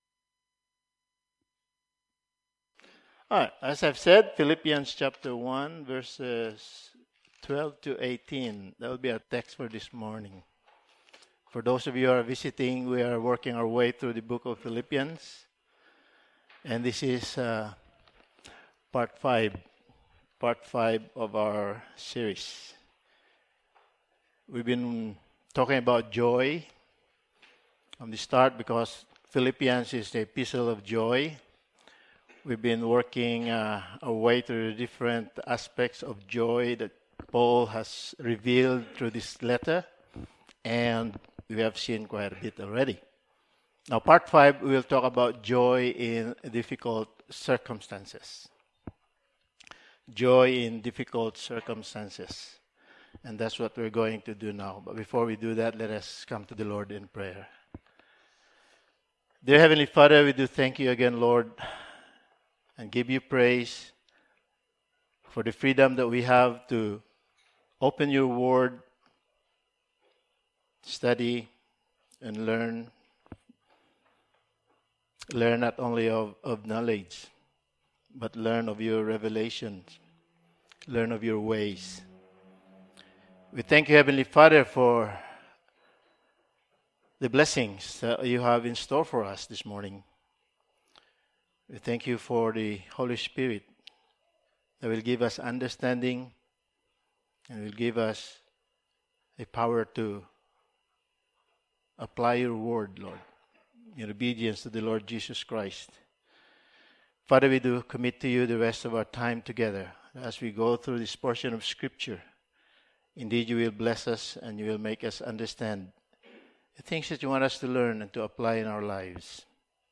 Passage: Philippians 1:12-18 Service Type: Sunday Morning